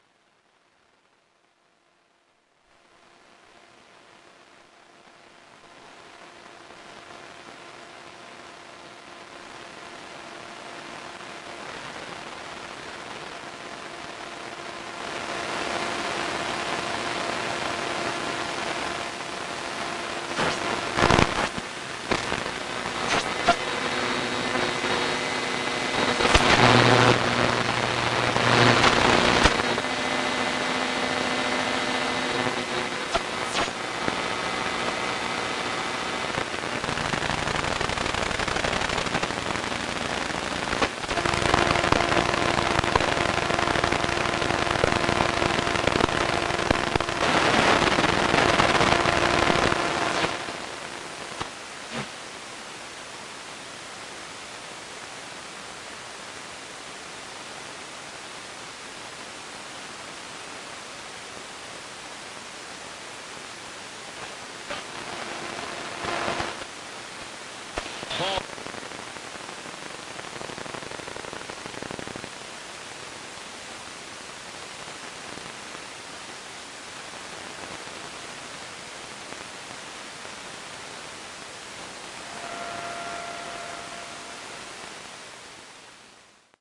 噪声 " 短波电台噪声3
描述：通过Twente大学宽带短波无线电调谐器网站从短波无线电静态录制的Buzzy噪声。听起来有点像直升机。
标签： 白噪声 无线电 短波 直升机 静态 嗡嗡声 刺耳噪音
声道立体声